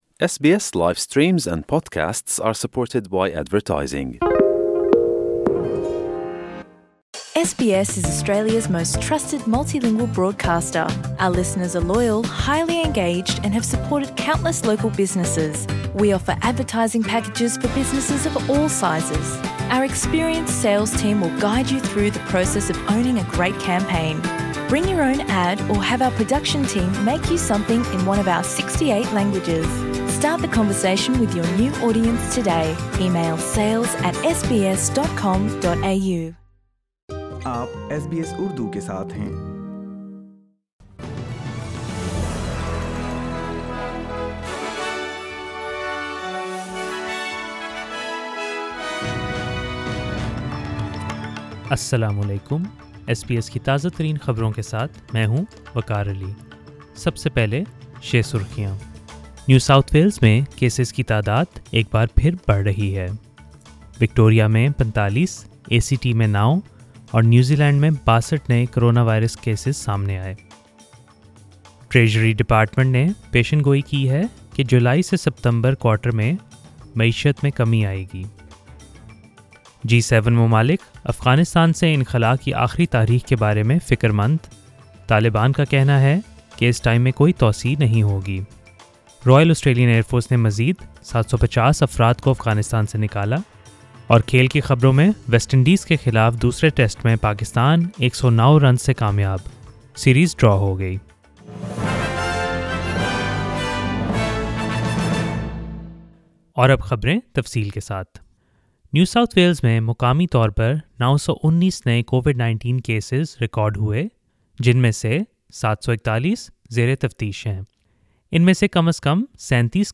SBS Urdu News 25 August 2021